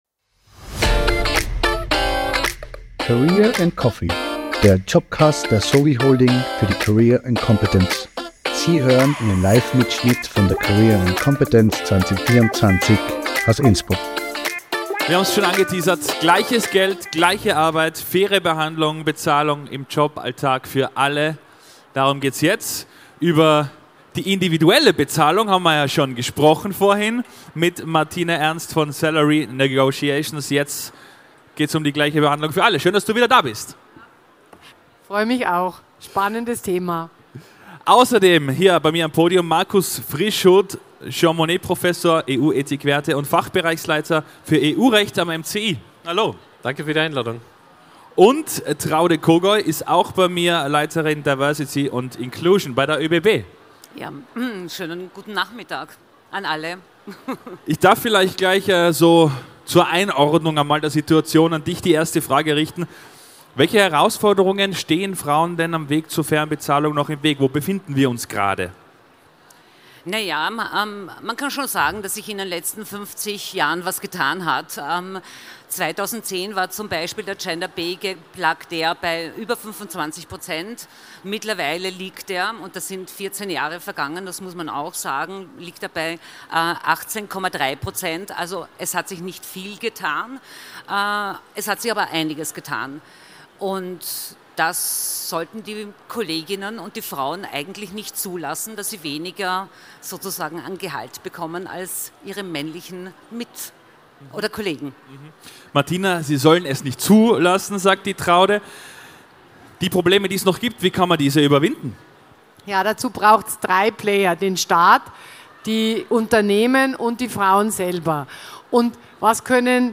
Wir beleuchten, wie Frauen aktiv Lohngleichheit fördern. Welche Erfolge, Herausforderungen und Strategien gibt es, um in der Arbeitswelt faire Bezahlung für alle zu erreichen? Livemitschnitt von der career & competence am 24. April 2024 im Congress Innsbruck.